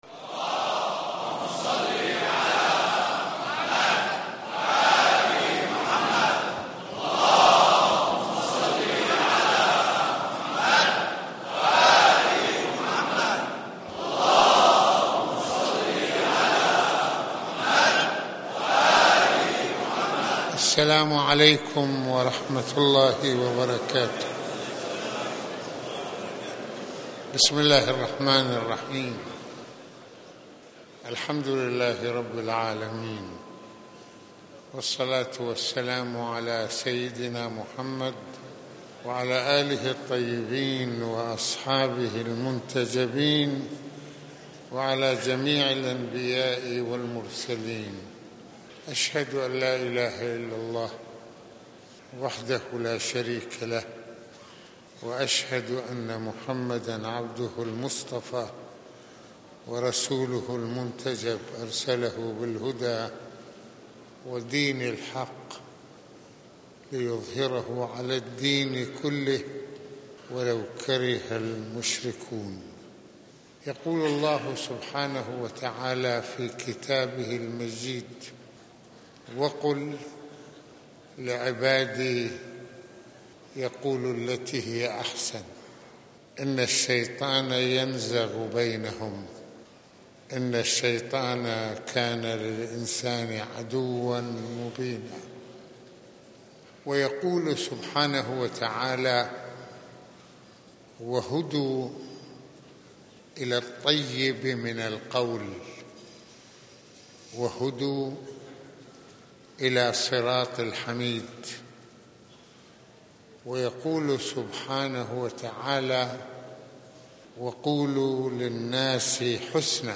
- المناسبة : خطبة الجمعة المكان : مسجد الإمامين الحسنين (ع) المدة : 22د | 06ث المواضيع : الكلام...